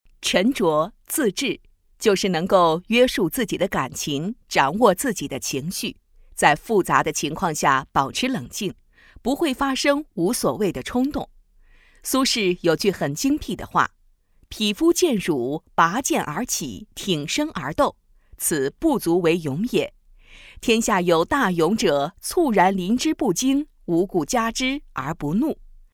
Chinese female voice over